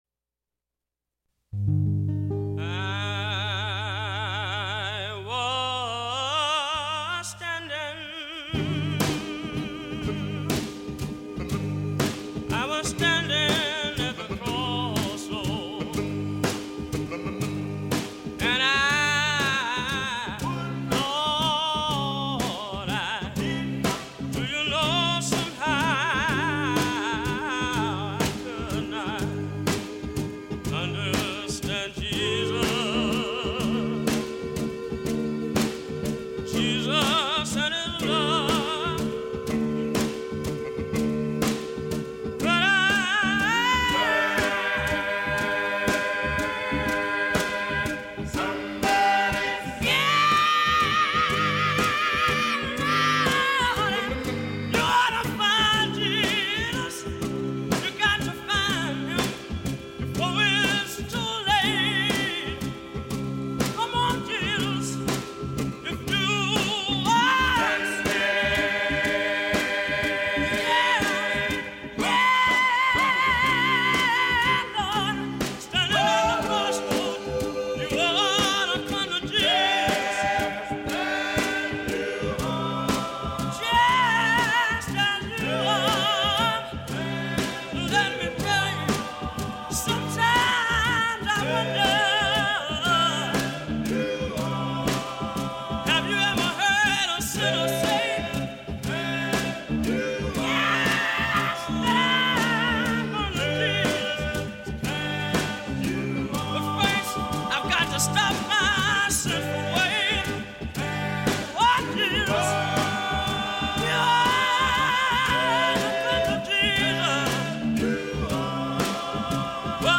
Recorded in the studio of WBIL-AM Tuskegee, Alabama. 2005